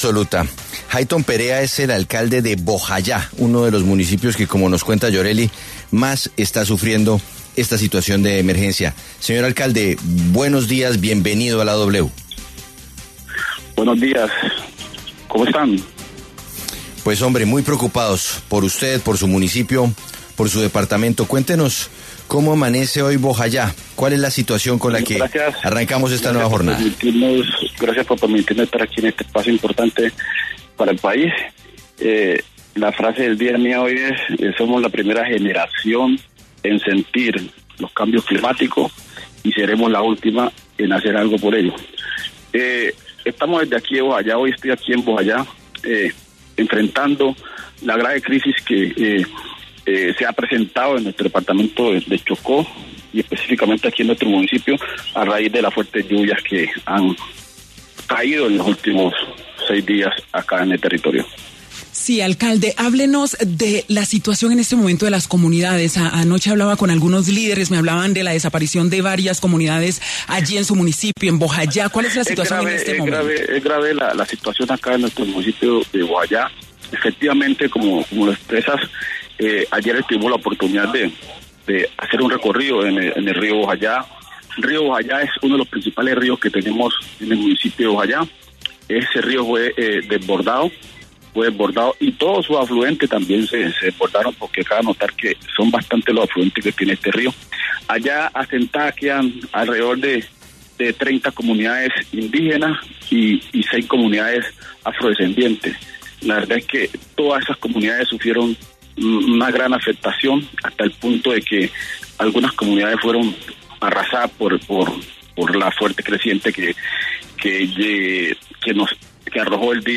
Hailton Perea, alcalde de Bojayá, uno de los municipios que más está sufriendo la situación de emergencia en el Chocó, pasó por los micrófonos de La W para hablar sobre la situación que vive su comunidad.